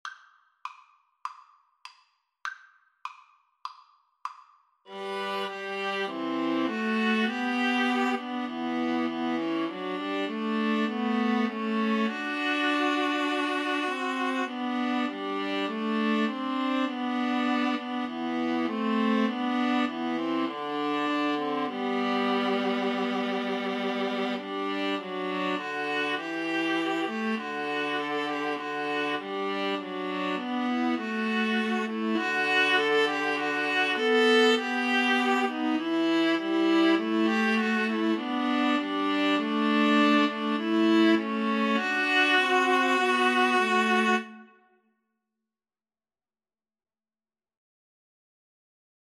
Free Sheet music for Viola Trio
G major (Sounding Pitch) (View more G major Music for Viola Trio )
4/4 (View more 4/4 Music)
Classical (View more Classical Viola Trio Music)